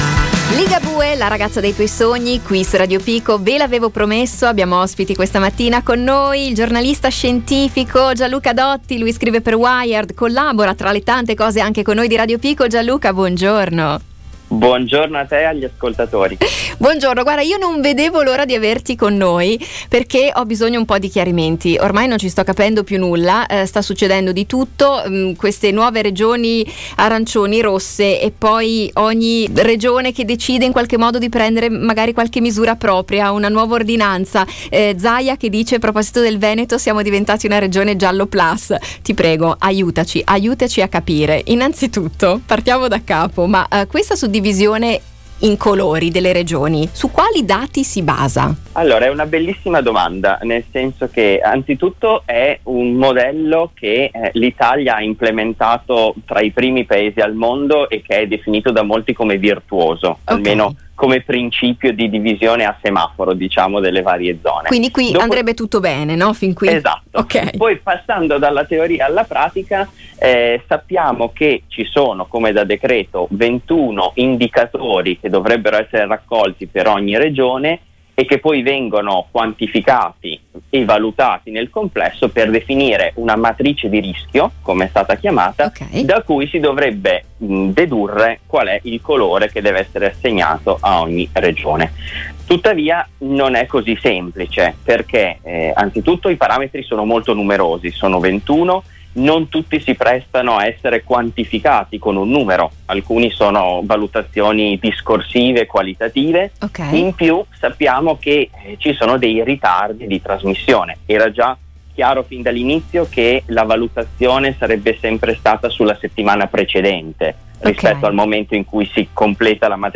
abbiamo intervistato il giornalista scientifico